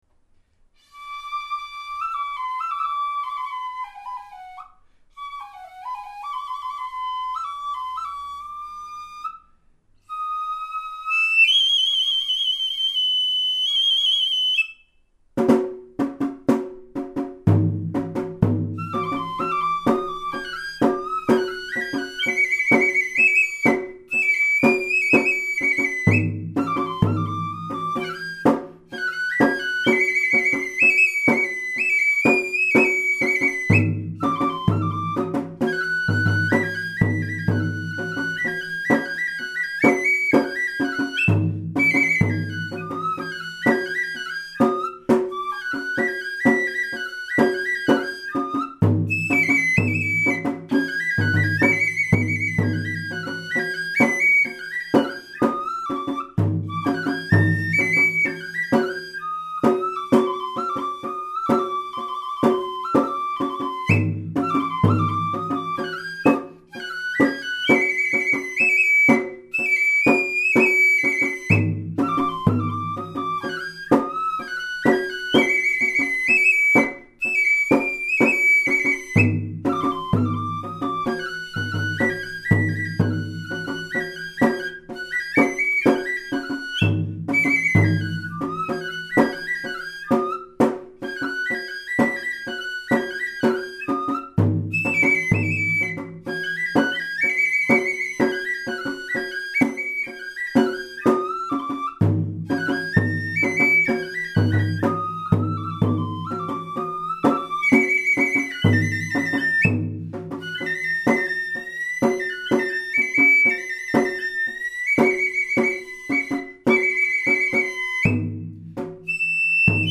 なお、笛の方はとっても上手ですが、太鼓（実は大部分が私）は数箇所間違っております。
吹き出しの高音や太鼓の入りは、知立でよく使われているパターンでやっています。